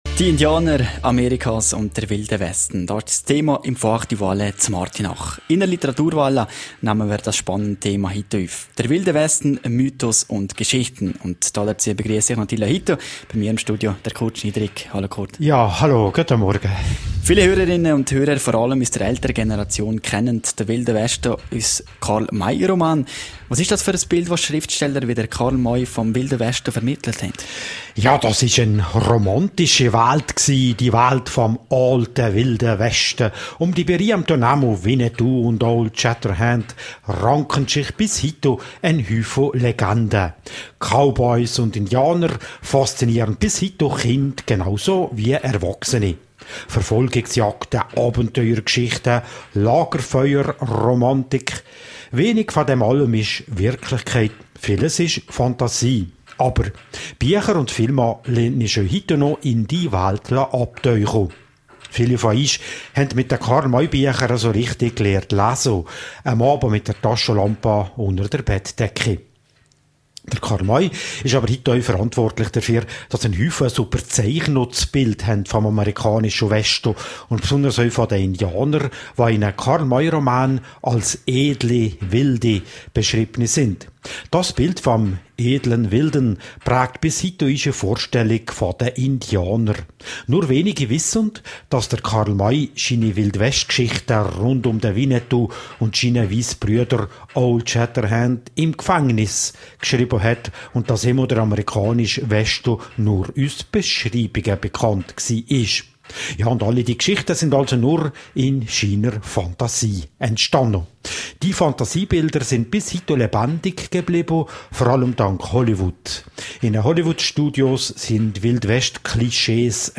Literaturwälla